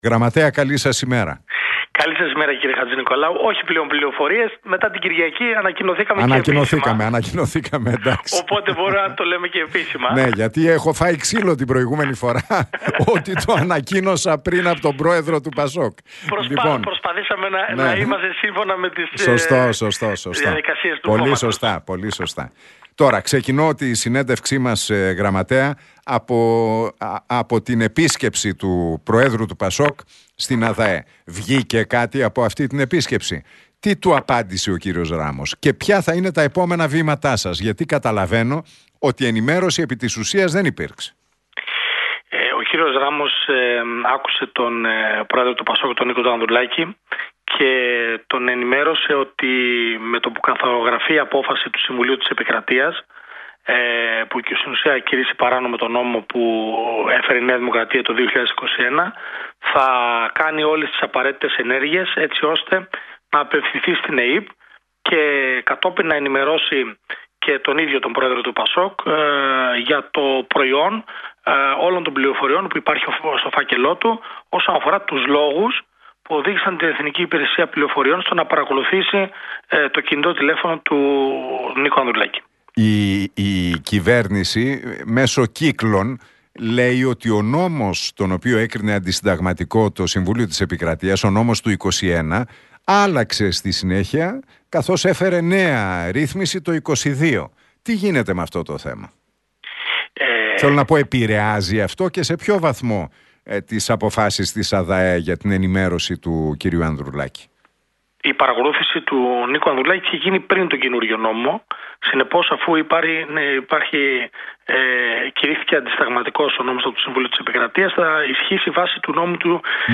Για τις υποκλοπές, την επίσκεψη του Νίκου Ανδρουλάκη στην ΑΔΑΕ αλλά και τις ευρωεκλογές μίλησε ο γραμματέας του ΠΑΣΟΚ και υποψήφιος ευρωβουλευτής, Ανδρέας Σπυρόπουλος στον Realfm 97,8 και την εκπομπή του Νίκου Χατζηνικολάου.